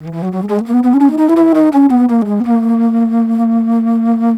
Flute 51-06.wav